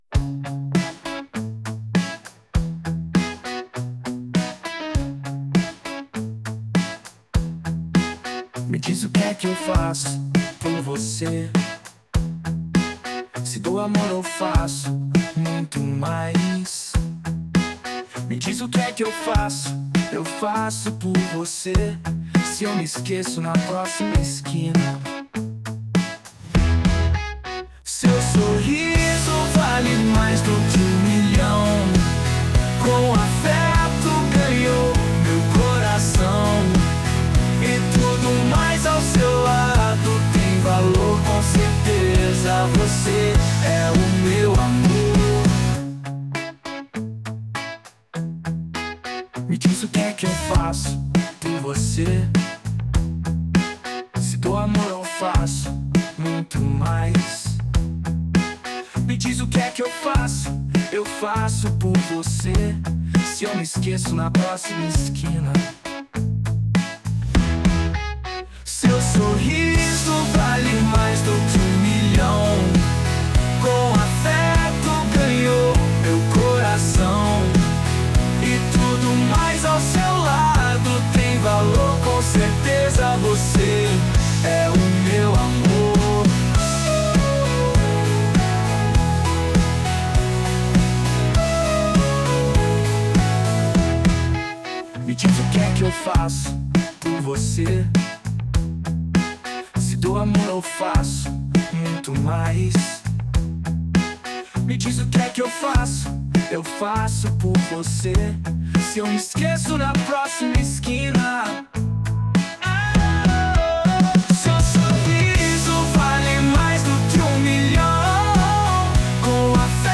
Transforme qualquer ideia em uma música incrível com voz, instrumentos de forma automática